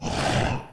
Goliath_voice_chargeshot.wav